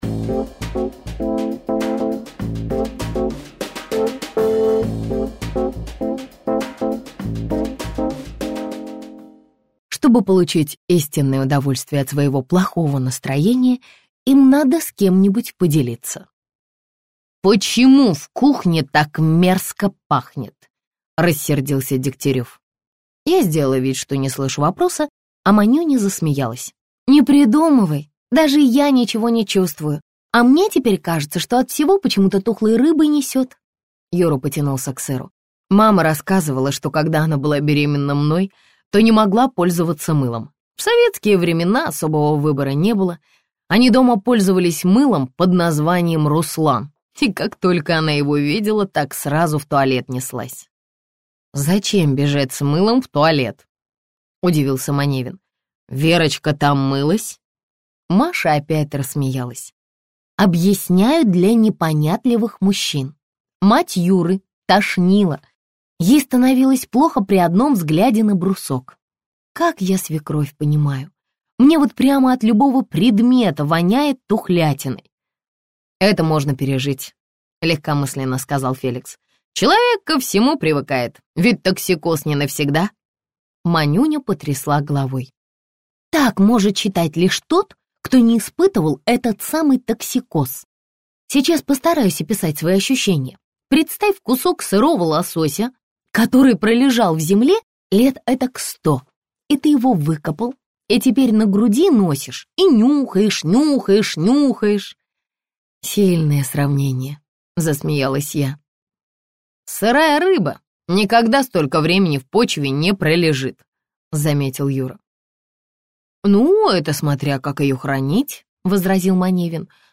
Аудиокнига Родословная до седьмого полена - купить, скачать и слушать онлайн | КнигоПоиск